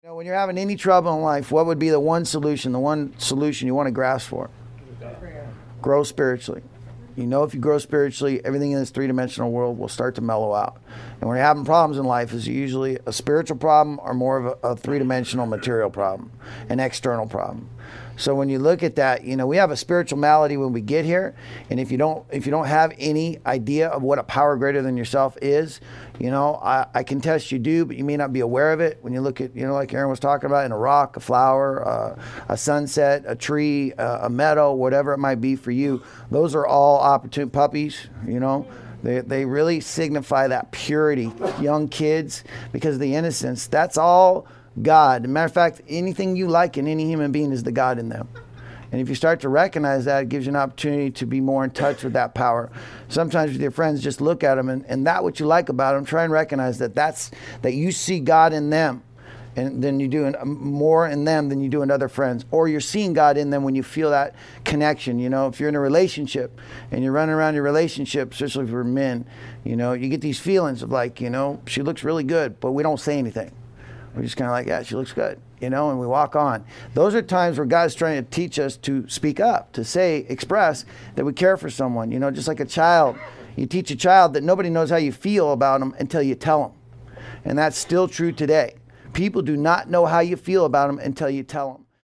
This audio archive is a compilation of many years of lecturing.
9.-Excerpt-Meditation-Contemplation.mp3